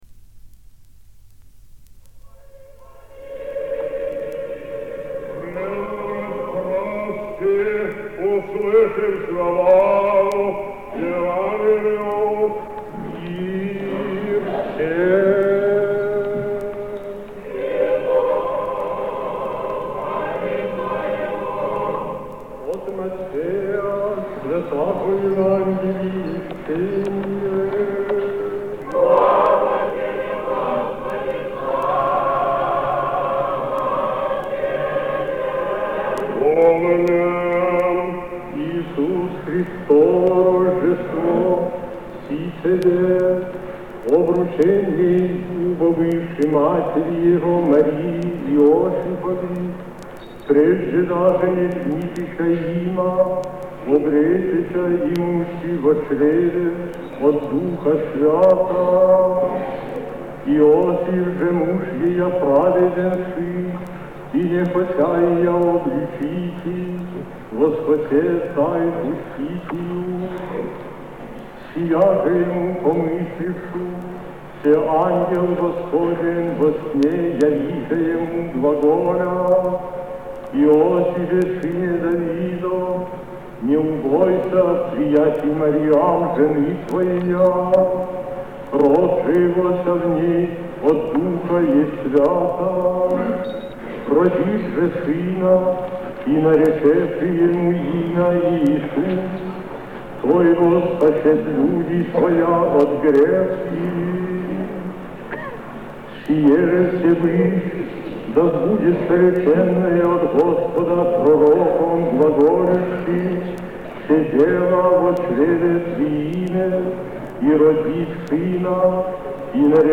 Запись с пластинки "Патриарх Алексий (Симанский) и его время"
3. Евангелие на вечерни Праздника Рождества Христова